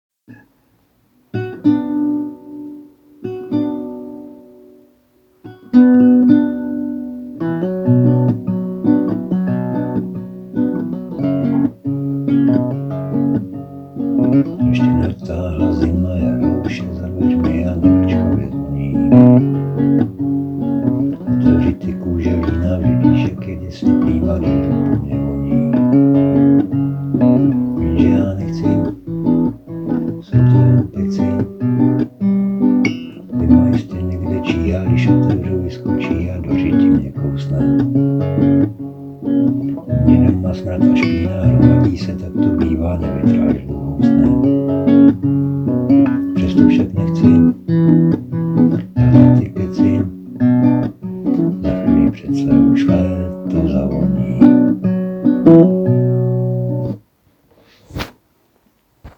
Anotace: Písničkobásničkokravinka č.2 Ještě to určitě přezpívám, je tam zatím moc chyb:-)
Jsi mne překvapil - moc hezky zpíváš a hraješ na kytaru.
Super, palec nahoru, hezká barva hlasu... přidávám se k dcérkám a říkám... pokračuj...
Je spíš šeptaná, než zpívaná... časem napravím...:-)